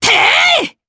Demia-Vox_Attack4_jp.wav